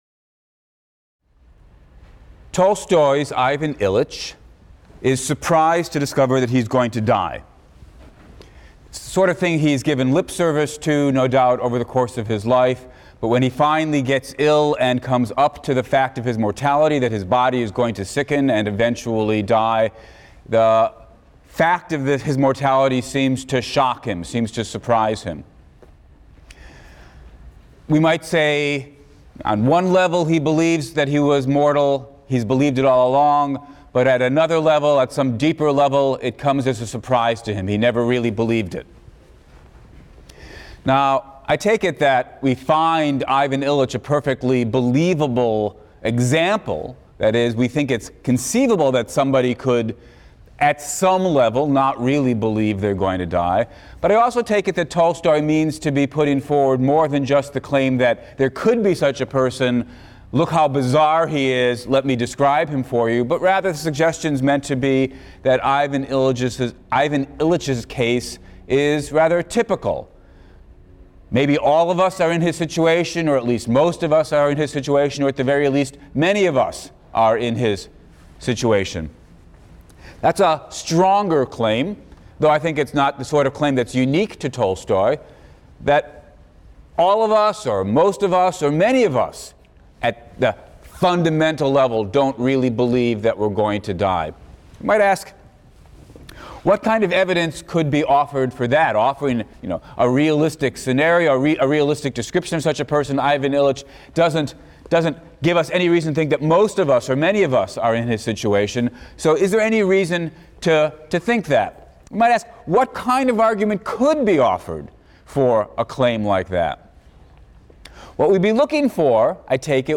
PHIL 176 - Lecture 16 - Dying Alone; The Badness of Death, Part I | Open Yale Courses